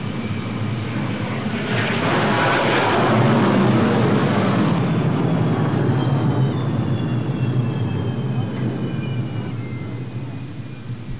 دانلود آهنگ طیاره 66 از افکت صوتی حمل و نقل
دانلود صدای طیاره 66 از ساعد نیوز با لینک مستقیم و کیفیت بالا
جلوه های صوتی